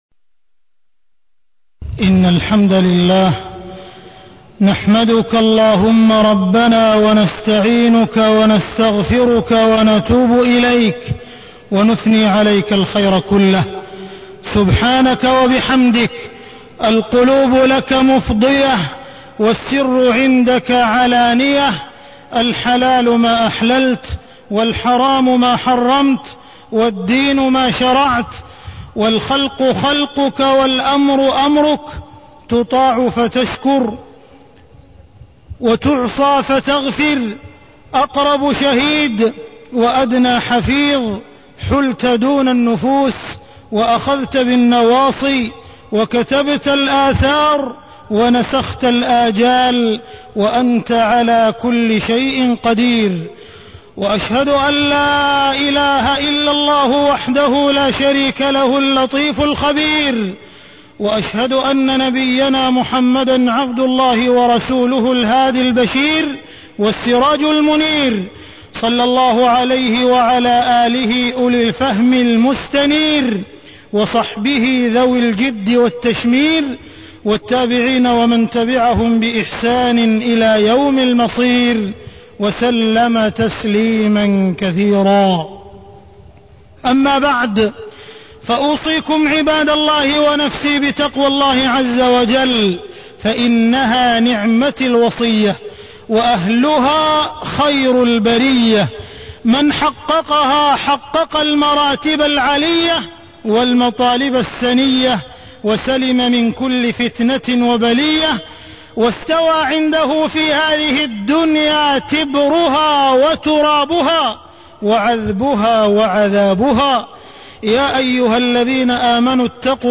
تاريخ النشر ١٤ محرم ١٤٢٥ هـ المكان: المسجد الحرام الشيخ: معالي الشيخ أ.د. عبدالرحمن بن عبدالعزيز السديس معالي الشيخ أ.د. عبدالرحمن بن عبدالعزيز السديس حرب المفاهيم